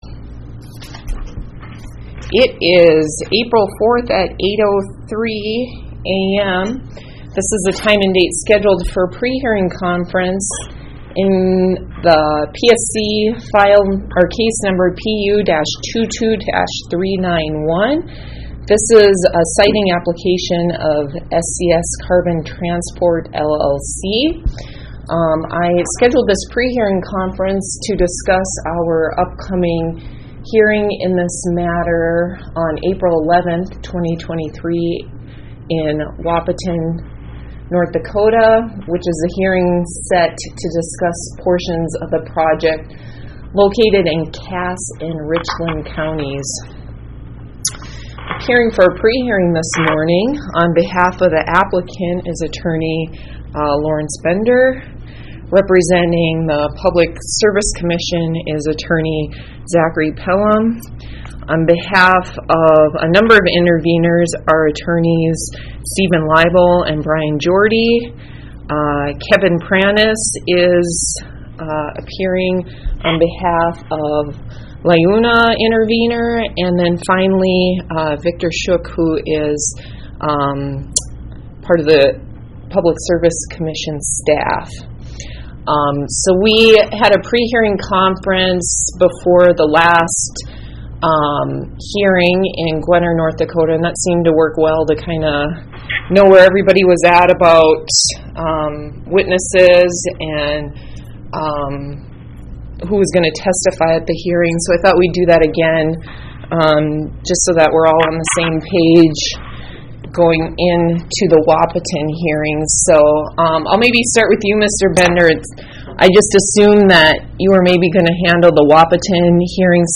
Electronic recording of 4 April 2023 prehearing conference